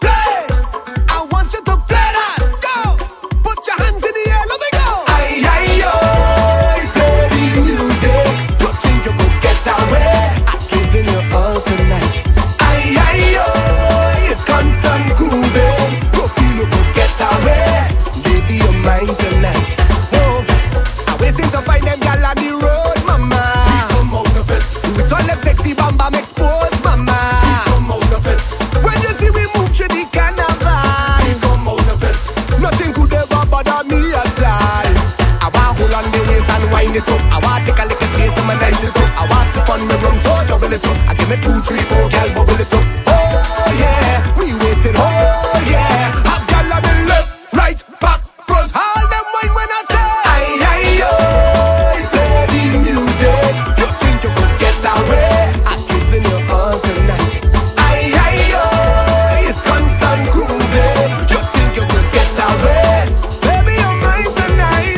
OTHER SOCA CDS